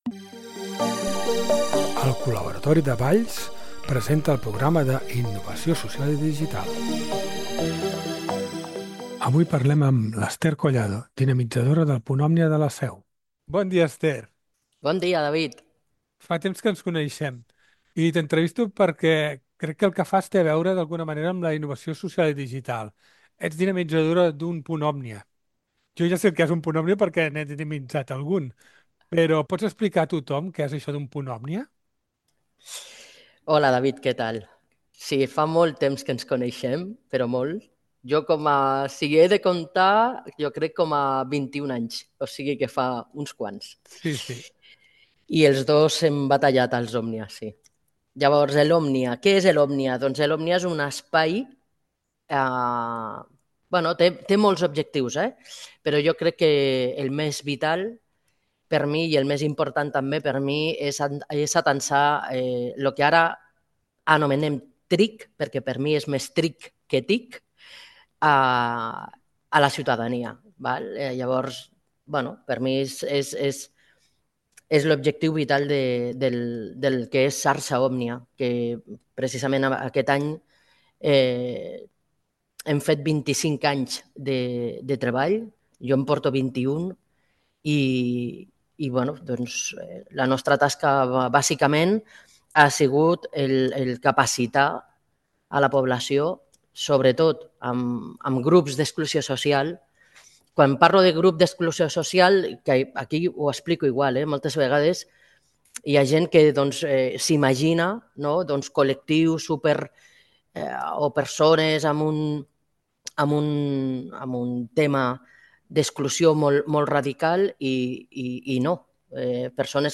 Avui entrevista